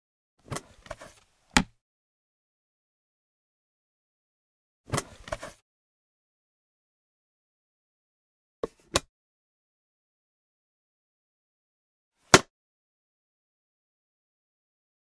household
Childs Plastic Lunch Box Open Lid